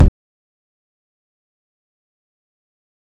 Kick (RoboCop).wav